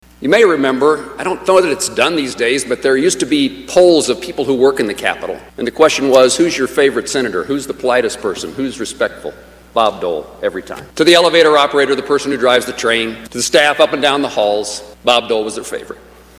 Senator Jerry Moran says, in an emotion filled speech, Dole was not only beloved by his colleagues at the US Capitol, but the staff who worked there as well.